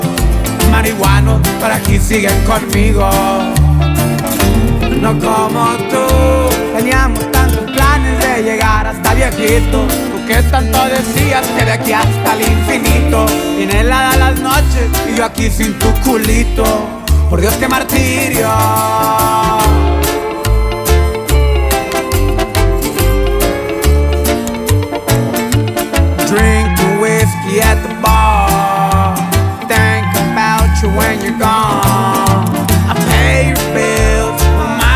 Música Mexicana Latin